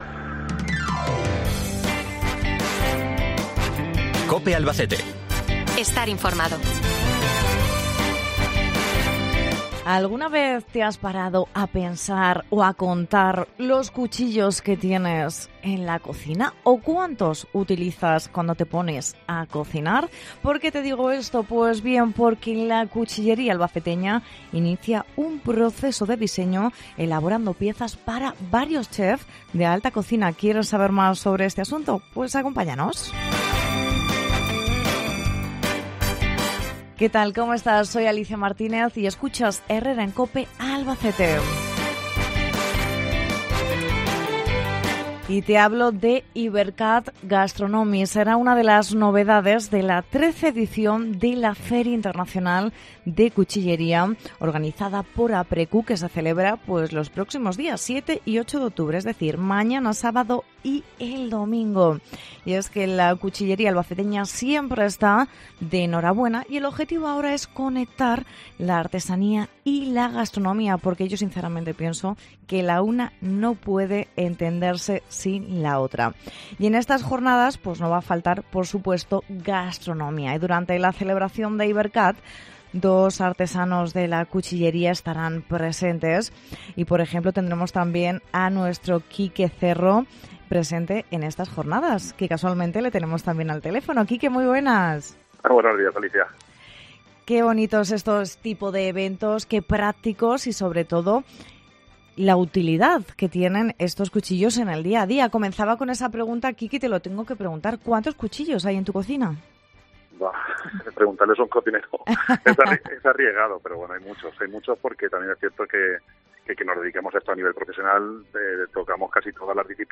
nos acompaña el Coro de Gospel One Soul de La Lira